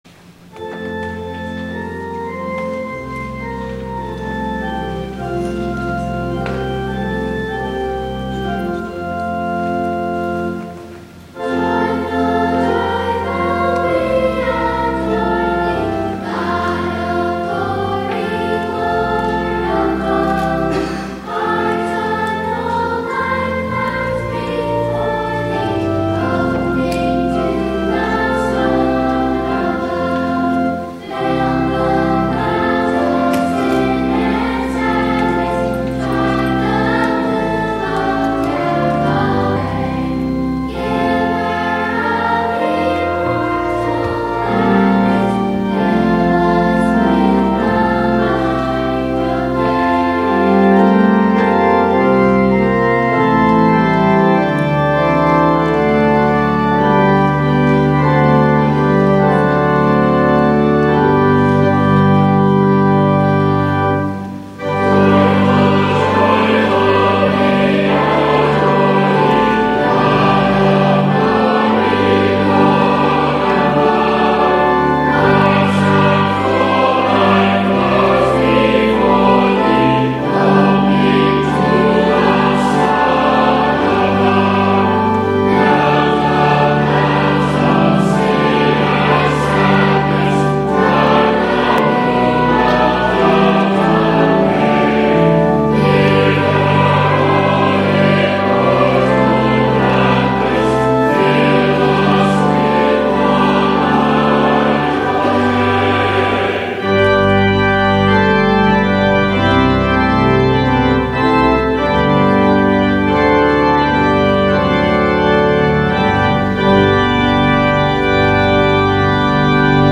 Treble Choir